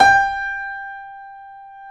Index of /90_sSampleCDs/Roland - Rhythm Section/KEY_YC7 Piano mf/KEY_mf YC7 Mono
KEY G 4 F 0H.wav